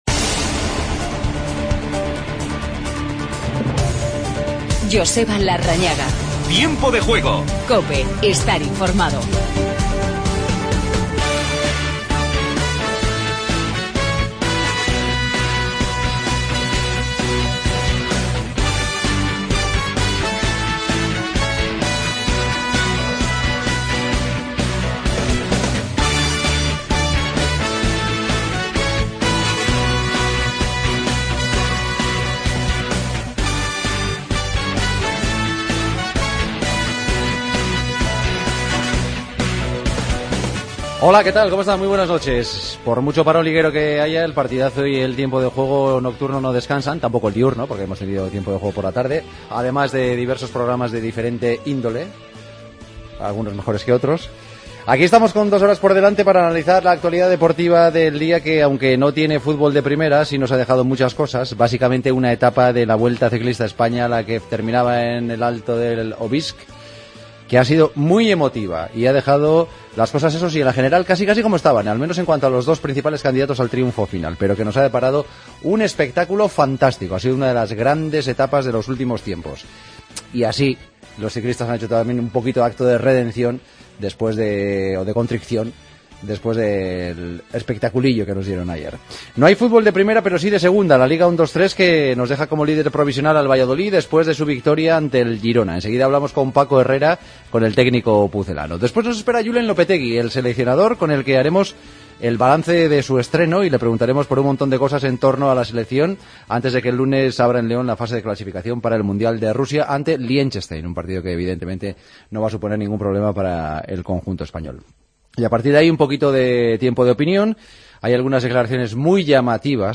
Hablamos con Paco Herrera. Entrevista a Julen Lopetegui.